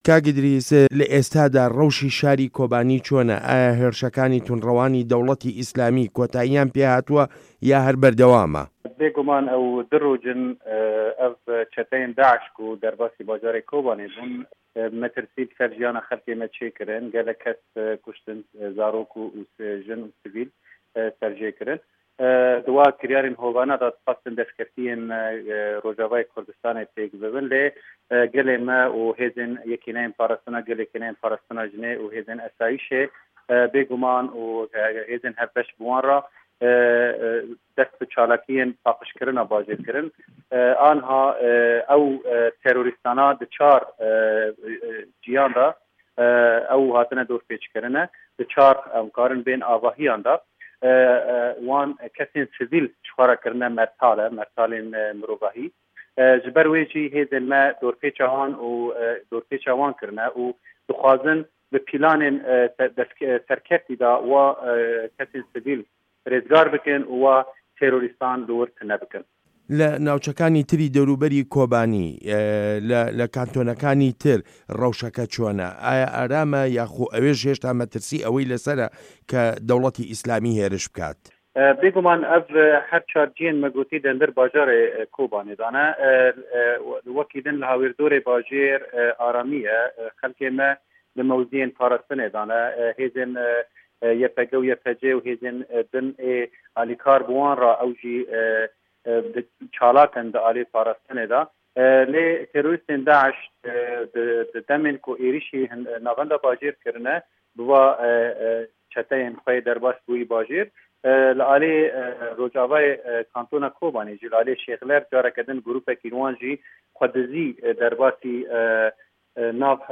وتووێژ له‌گه‌ڵ ئیدریس نه‌عسان